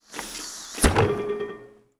Abrir la puerta de un congelador con ventosa
Sonidos: Acciones humanas
Sonidos: Hogar